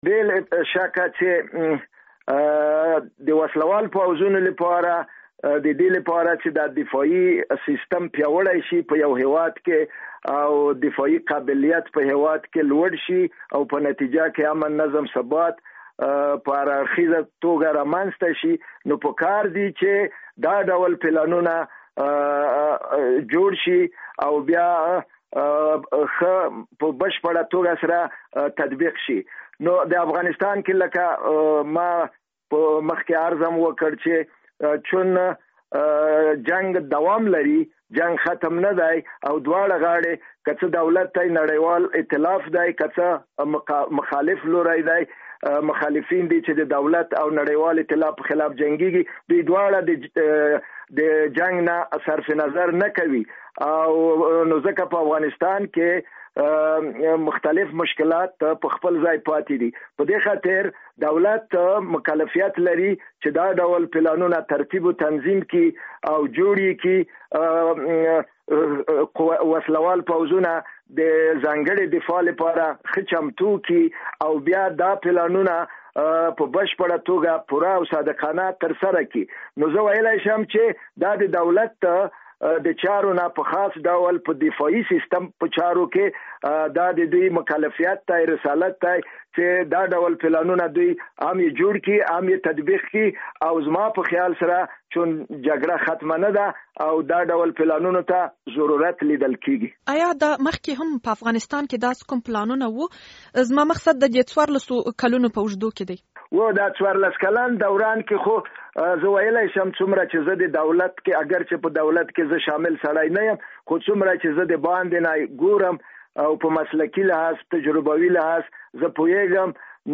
له شاه نواز تڼي سره مرکه